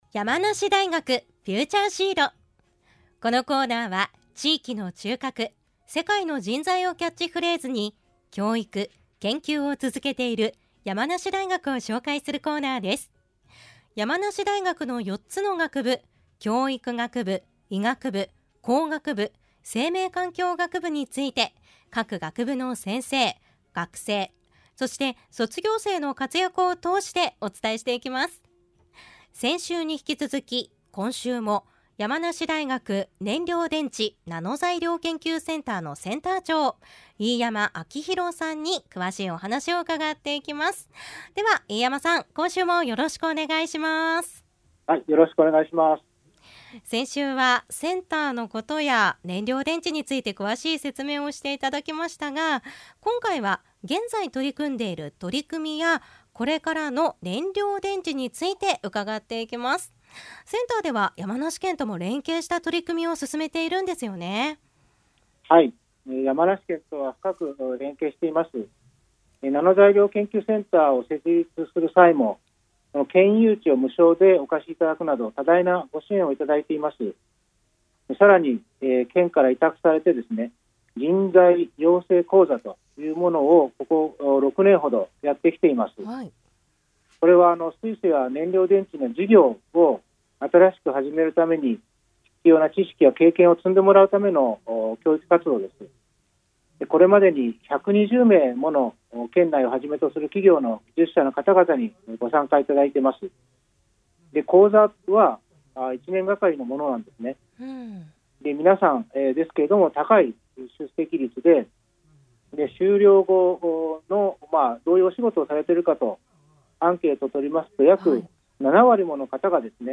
電話でお話を伺いました。